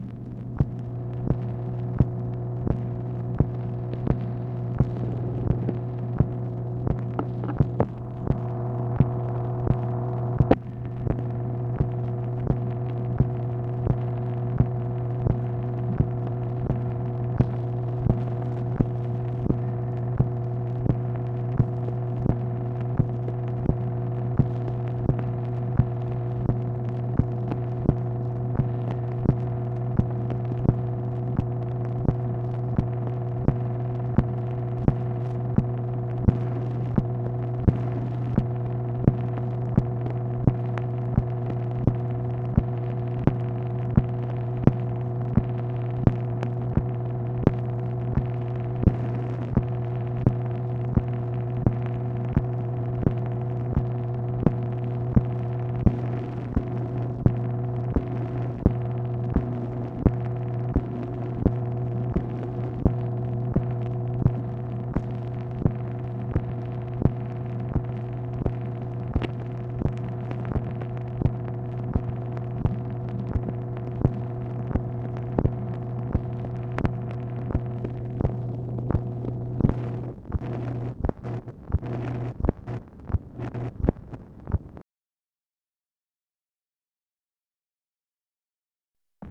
MACHINE NOISE, May 2, 1965
Secret White House Tapes | Lyndon B. Johnson Presidency